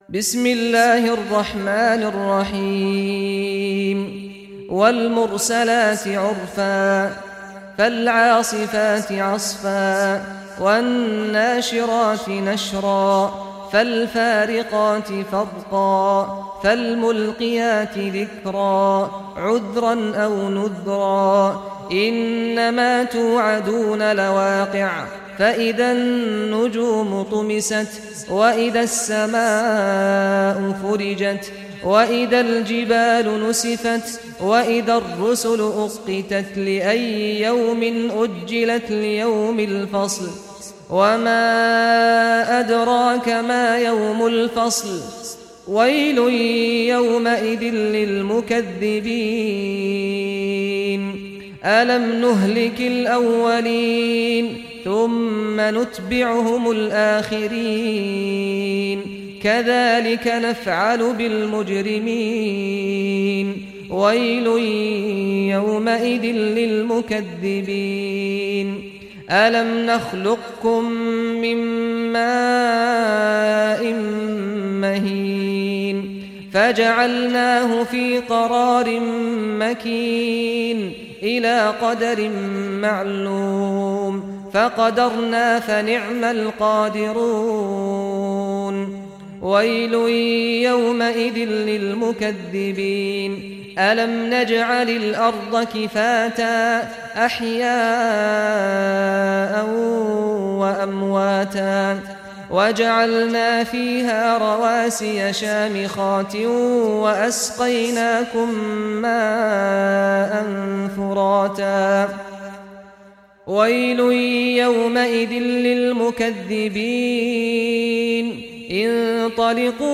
Surah Al-Mursalat Recitation by Sheikh Saad Ghamdi
Surah Al-Mursalat, listen or play online mp3 tilawat / recitation in Arabic in the beautiful voice of Sheikh Saad al Ghamdi.